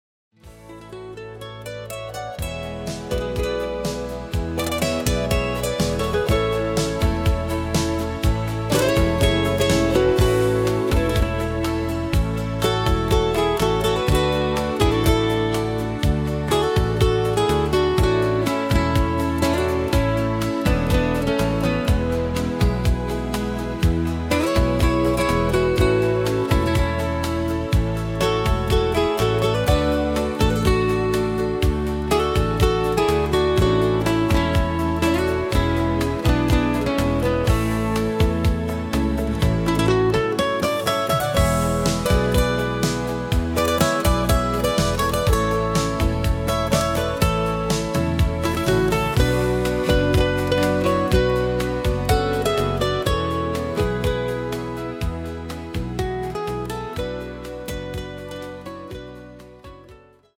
Musik erinnert an einen anderen Schlager